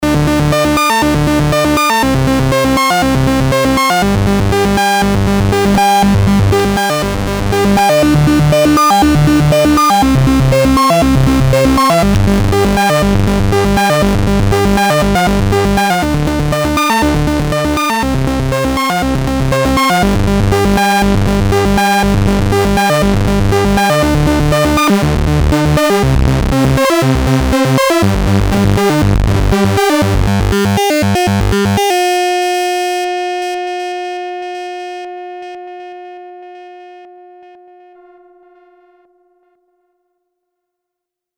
Monophonic portable synthesizer: 2 oscillator with a complex and smart analog signal patch routing.
demo AUDIO DEMO